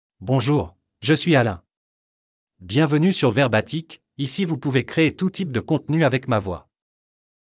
Alain — Male French (France) AI Voice | TTS, Voice Cloning & Video | Verbatik AI
AlainMale French AI voice
Voice sample
Listen to Alain's male French voice.
Alain delivers clear pronunciation with authentic France French intonation, making your content sound professionally produced.